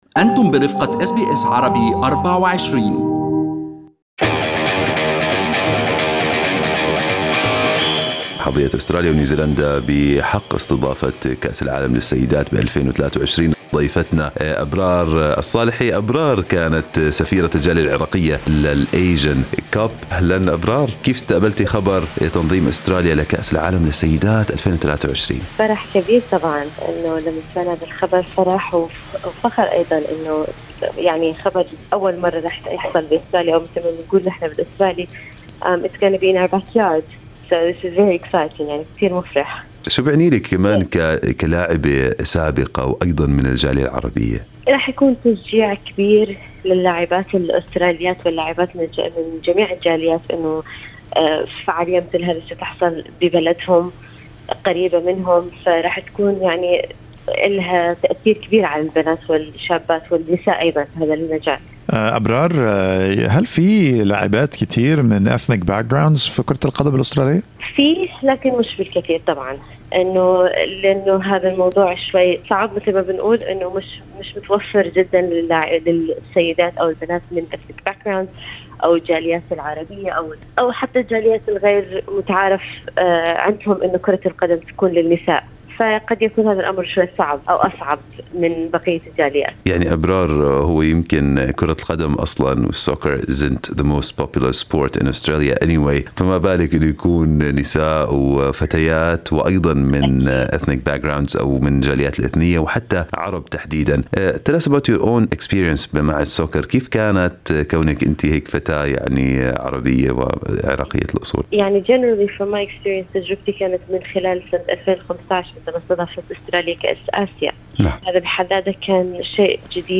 لاعبة كرة قدم سابقة من أصول عراقية تروي تجربتها في عالم كرة القدم.